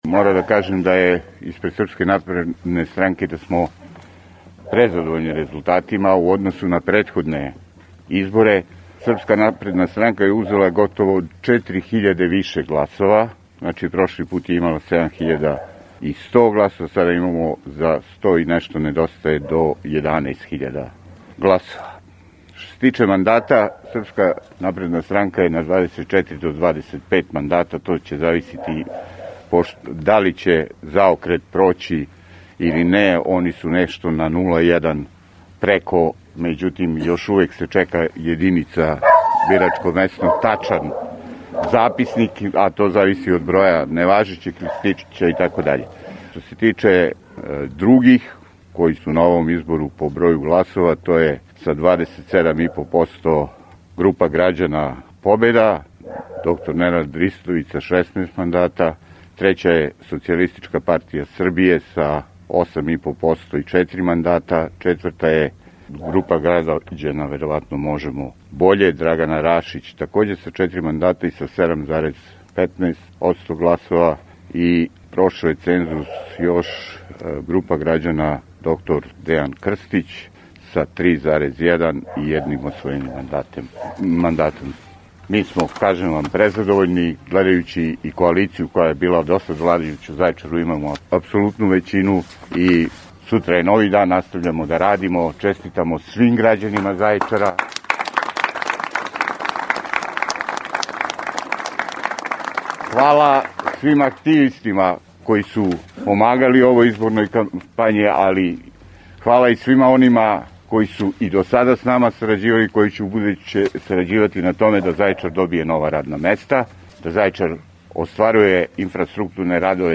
Ispred izbornog štaba Srpske napredne stranke u Zaječaru večeras se, pola sata pre ponoći, medijima obratio Boško Ničić, aktuelni gradonačelnik Zaječara i prvi na listi Aleksandar Vučić – Za budućnost Zaječara.